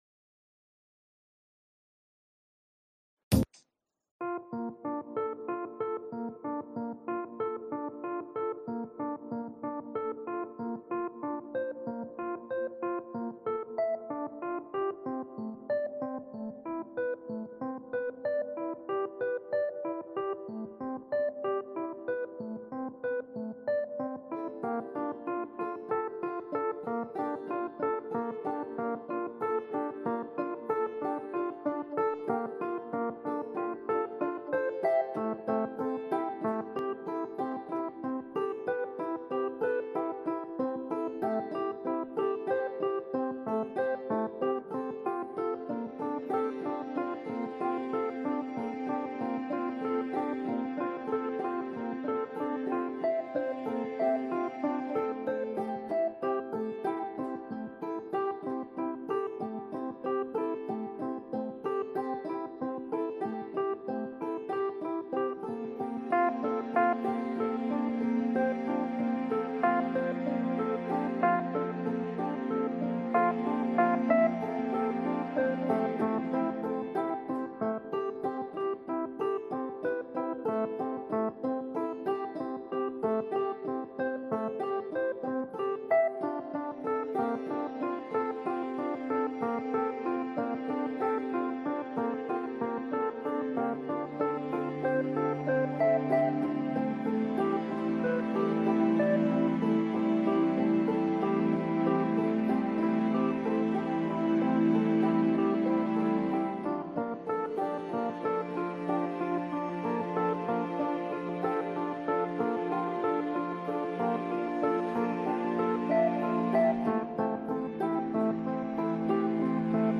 Bibliothèque des rediffusions (Spaces Twitter) extraites.